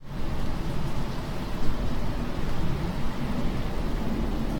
while_jumping.ogg